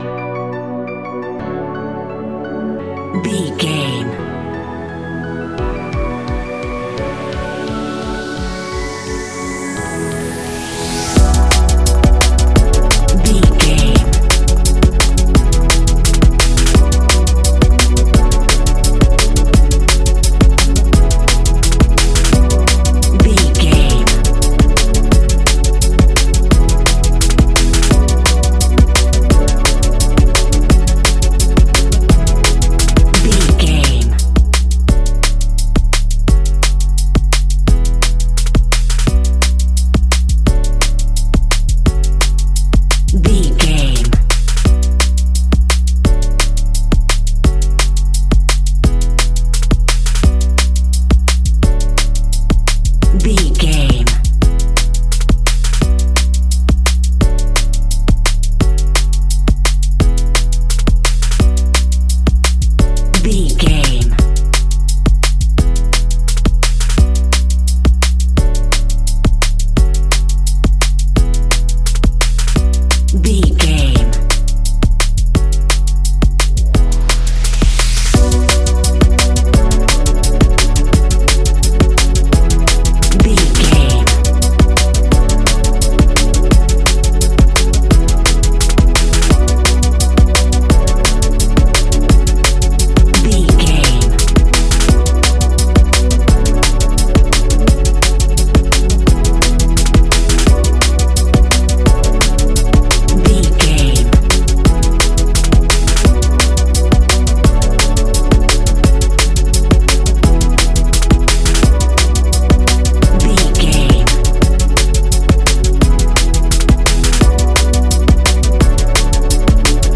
Ionian/Major
electronic
techno
trance
synths
synthwave
instrumentals